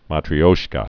(mätrē-ōshkə)